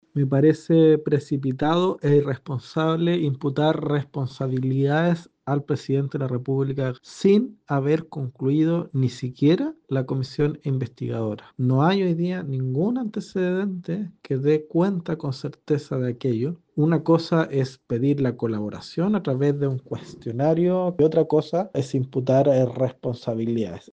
El diputado PPD, Raúl Soto, cuestionó la rapidez con la que las fuerzas opositoras parecen haber concluido que el Presidente Boric es personalmente responsable.